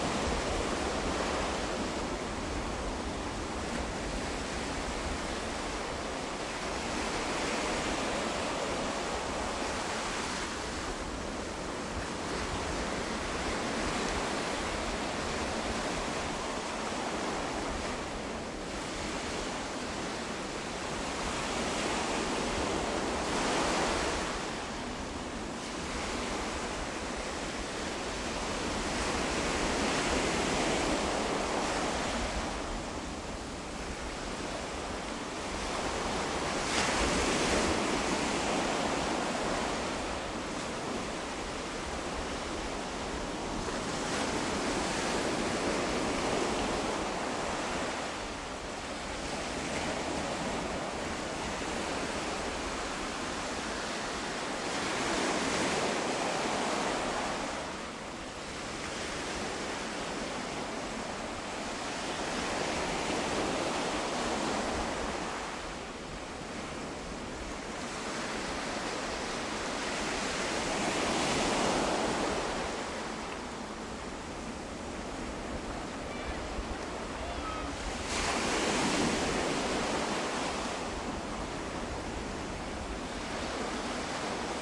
船舶喇叭汽笛声
描述：远洋巡航线海洋船舶喇叭低音汽笛声。
标签： 海滩 线 波浪 巡航 喇叭 大海 海洋 远洋巡航线 船舶喇叭
声道立体声